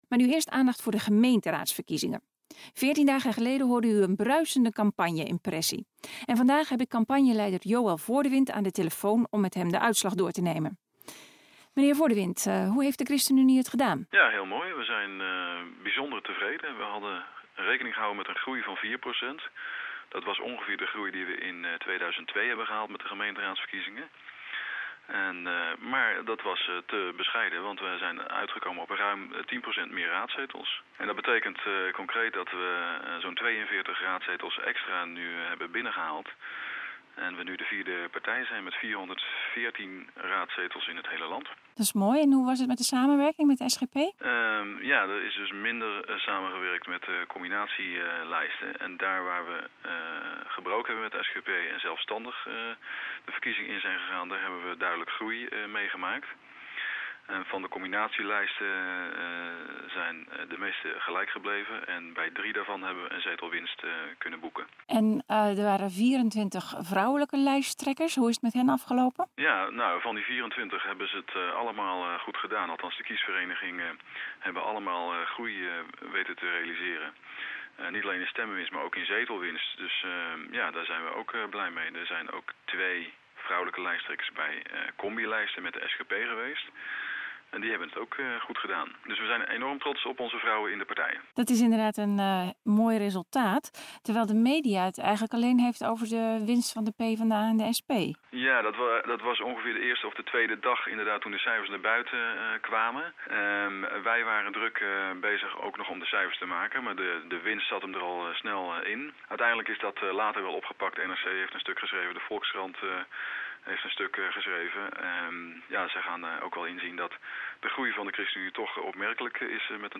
In deze radio uitzending hoort u Joël Voordewind over de uitslagen van de gemeenteraadsverkiezingen van 7 maart 2006.
Interview 64 kbit